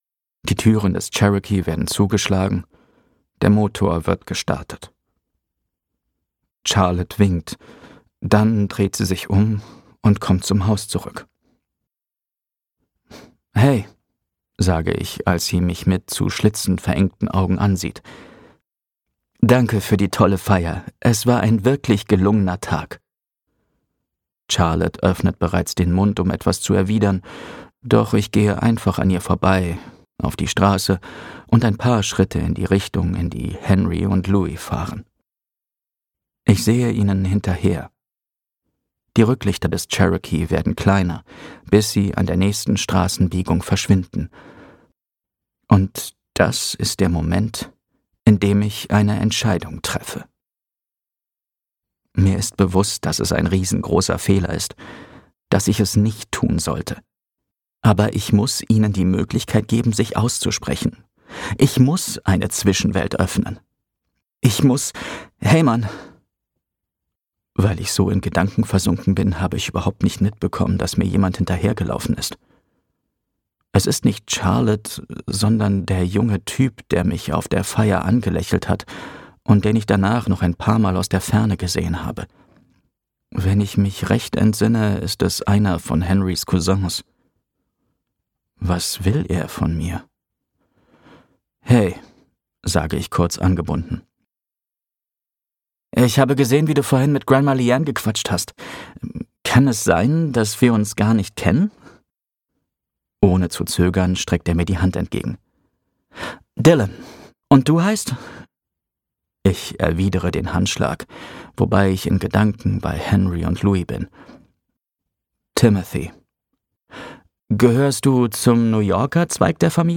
Heute sind wir unsterblich - Dominik Gaida | argon hörbuch
Gekürzt Autorisierte, d.h. von Autor:innen und / oder Verlagen freigegebene, bearbeitete Fassung.